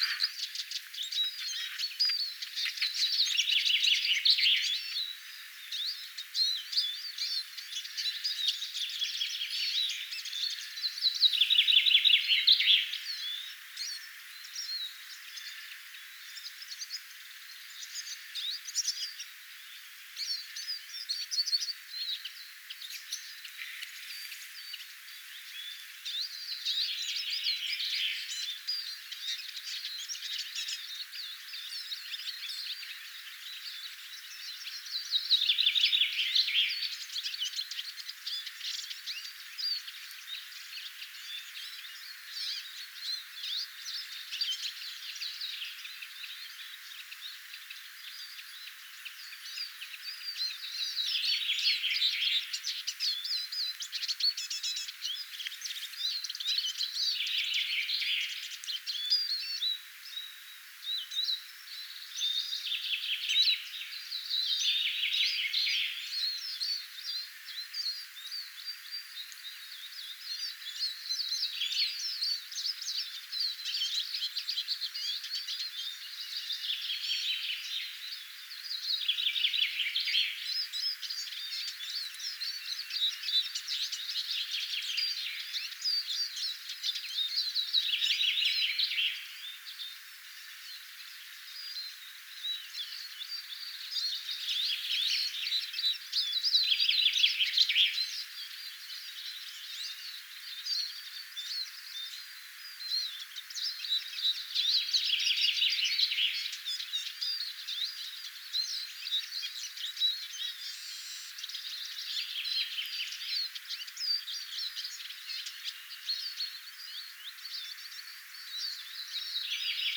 vihervarpusten laulua
vihervarpusten_laulua.mp3